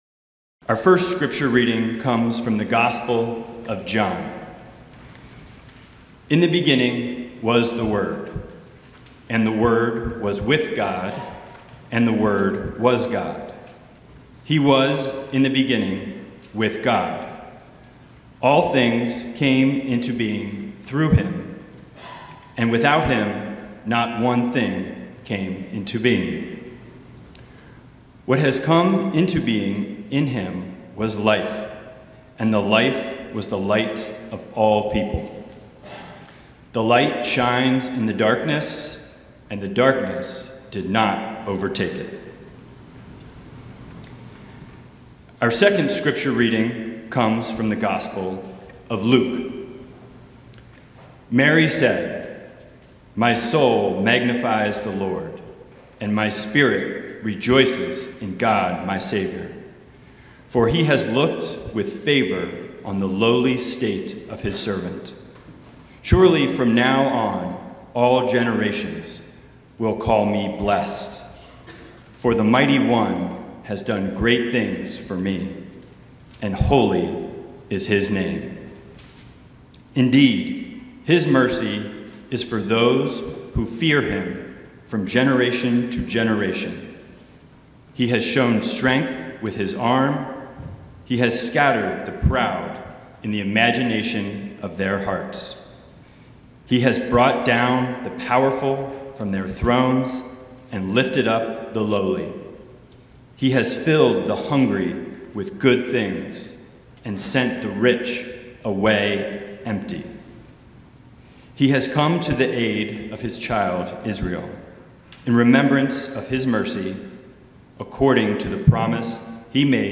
Sermon-March-1-2026-God-is-With-Us-Incarnation.mp3